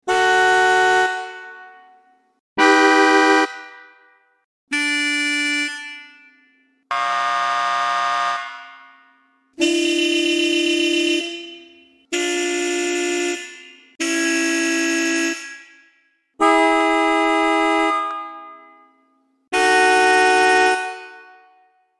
Звуки гудка автомобиля
Звуки автомобильных гудков (клаксонов): Подборка сигналов разных машин для монтажа